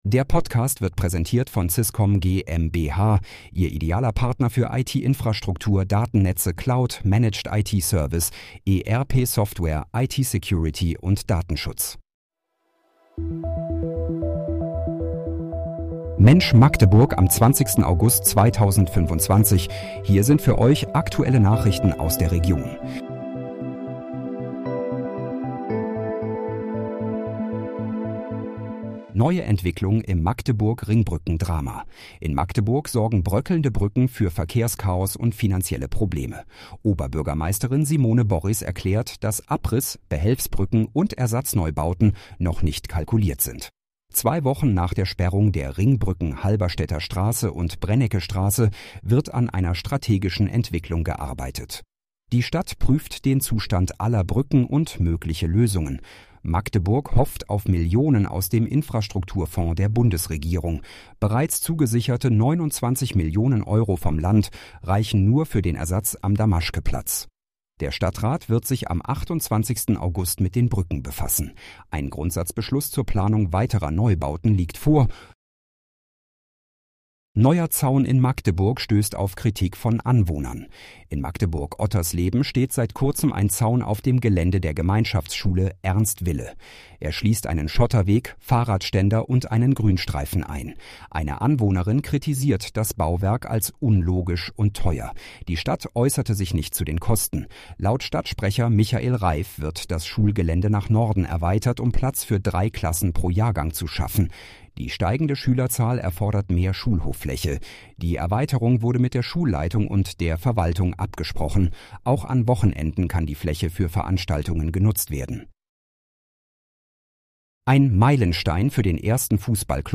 Mensch, Magdeburg: Aktuelle Nachrichten vom 20.08.2025, erstellt mit KI-Unterstützung
Nachrichten